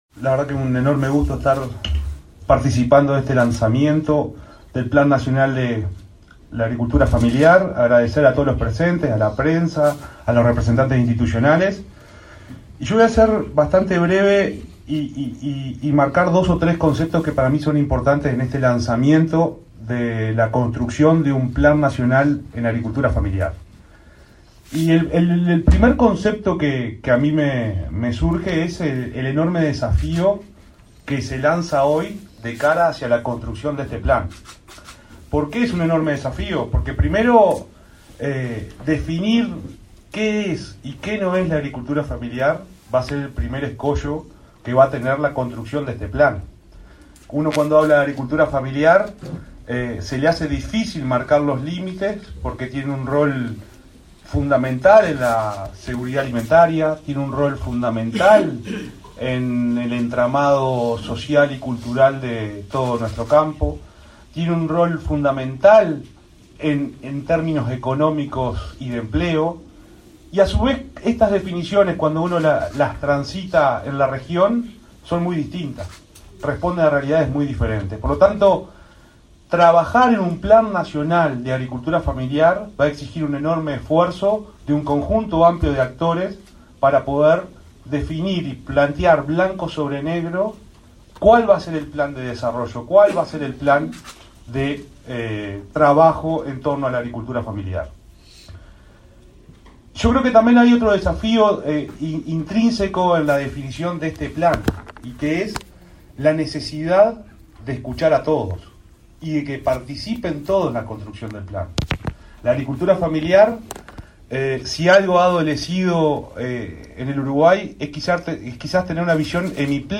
Acto de presentación del Plan Nacional de Agricultura Familiar en Uruguay
Las autoridades del Ministerio de Ganadería, Agricultura y Pesca (MGAP) presentaron, este 31 de agosto, las pautas para el desarrollo del Plan Nacional de Agricultura Familiar en Uruguay, luego de realizada la sesión en Uruguay de la Reunión Especializada de Agricultura Familiar (REAF), cuya presidencia “pro tempore” ejerce Brasil. Participaron en el evento el subsecretario de la cartera, Juan Ignacio Buffa, y la directora general del organismo y coordinadora nacional de la REAF, Fernanda Maldonado.